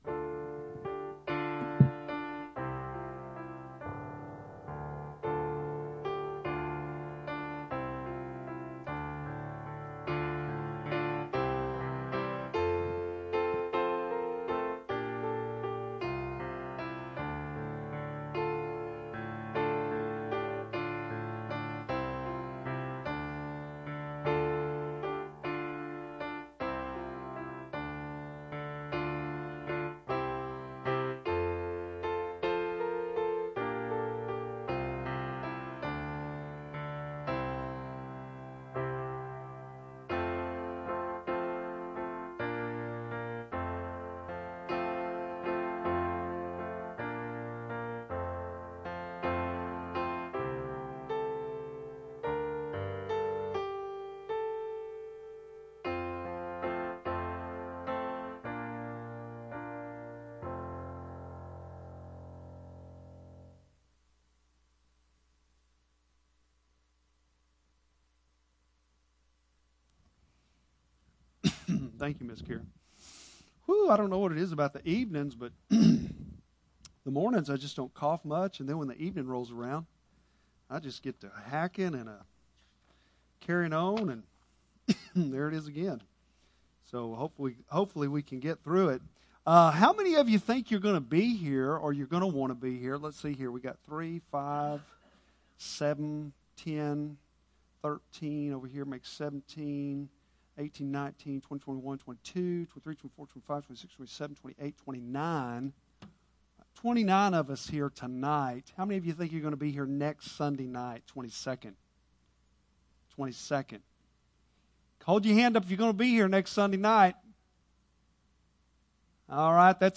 Bible Text: Revelation 11:1-14 | Preacher: